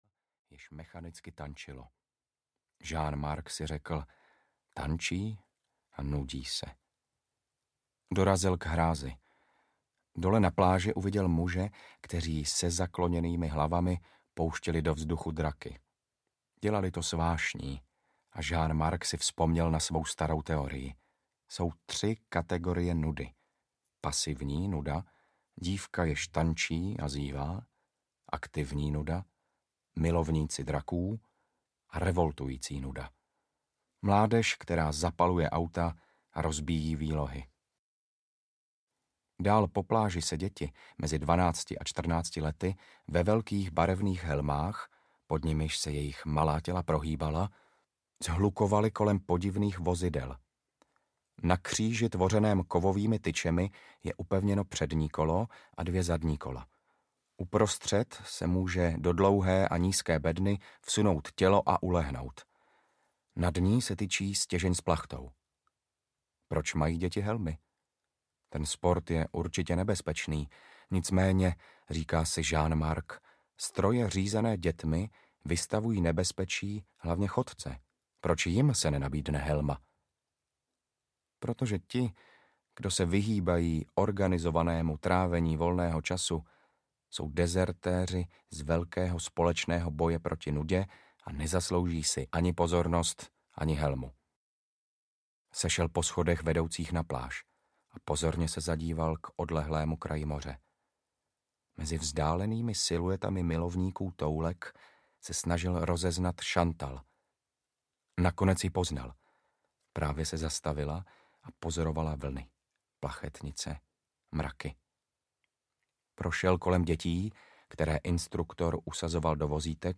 Totožnost audiokniha
Ukázka z knihy
Radúz Mácha svým přednesem (věcným, ale též nejen latentně dramatickým) zdůrazňuje existenciální polohu vyprávění jako soustředěného tázání po jedinečnosti člověka.
• InterpretRadúz Mácha